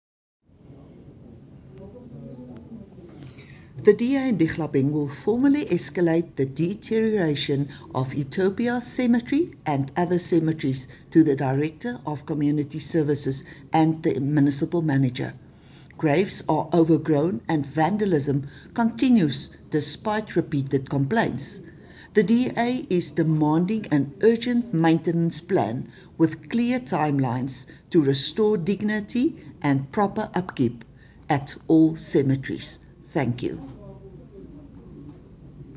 English and Afrikaans soundbites by Cllr Estie Senekal and Sesotho soundbite by Jafta Mokoena MPL with images here, here, and here
Neglected-cemeteries-Dihlabeng-English.mp3